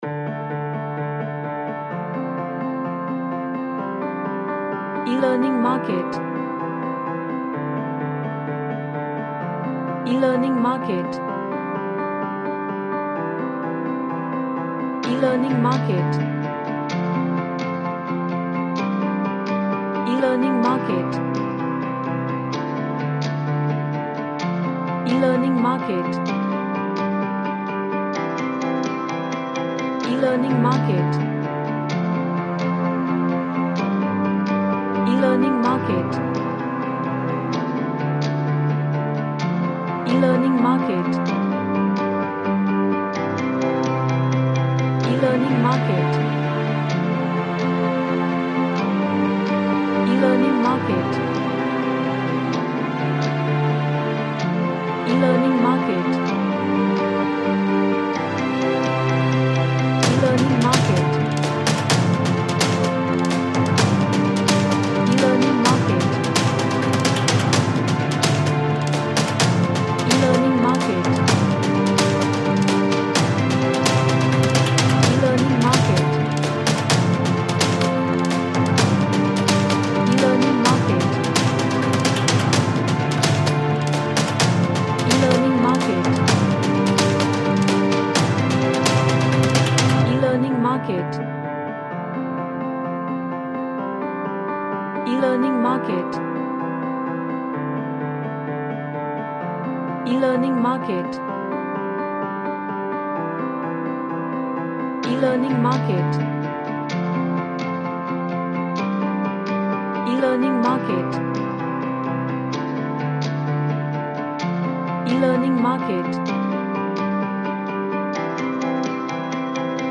A soft orchestral track